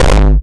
condense kick.wav